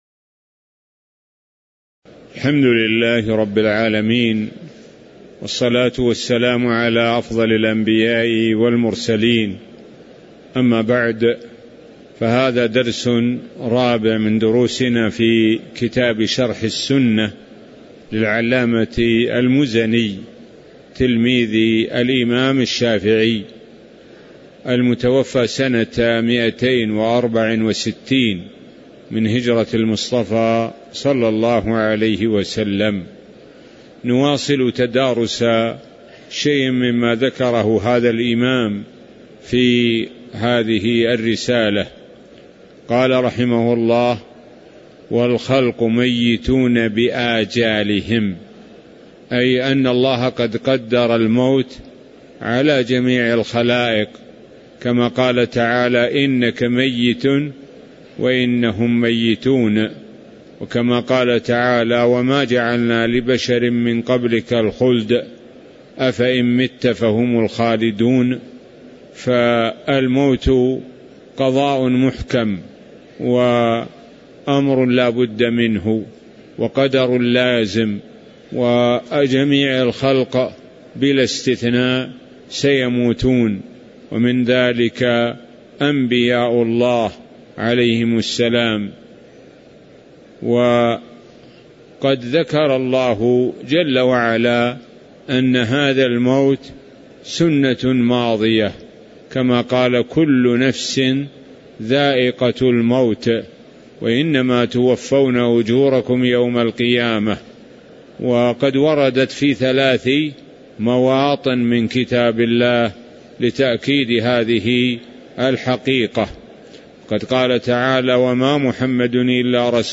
تاريخ النشر ١٨ ذو القعدة ١٤٤٣ هـ المكان: المسجد النبوي الشيخ: معالي الشيخ د. سعد بن ناصر الشثري معالي الشيخ د. سعد بن ناصر الشثري خلق ميتون بآجالهم (05)